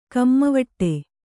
♪ kammavaṭṭe